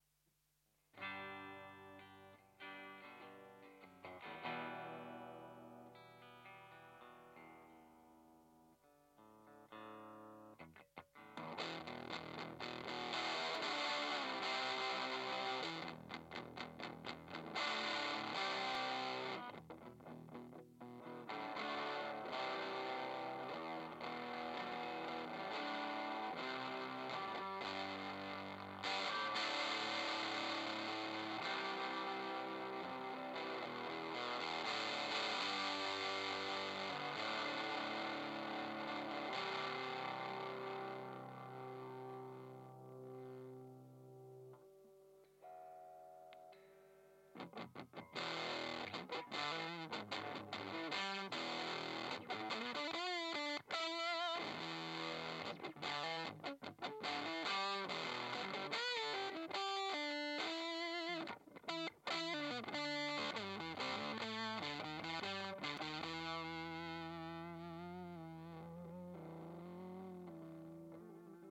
Raw-songs
Punk
Rock & Roll